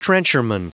Prononciation du mot trencherman en anglais (fichier audio)
Prononciation du mot : trencherman